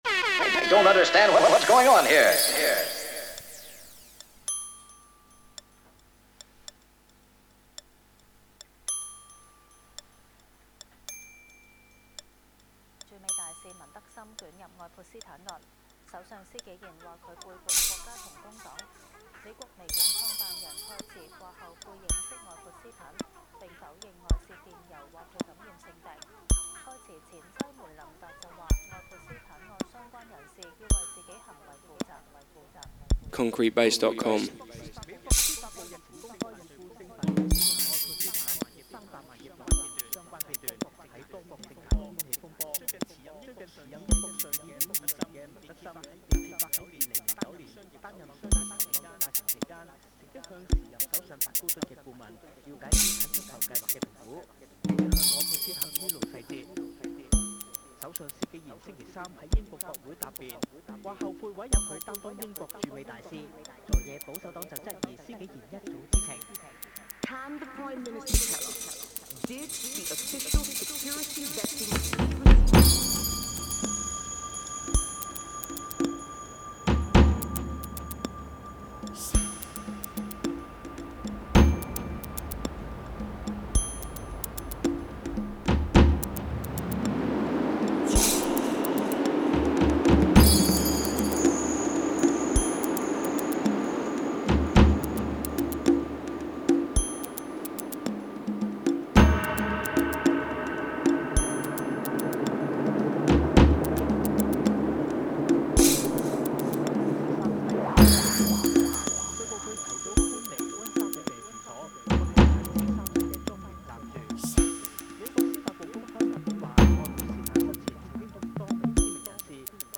DnB/Dubstep/Bass Music Mixes
Drum and Bass / Bass Music Podcast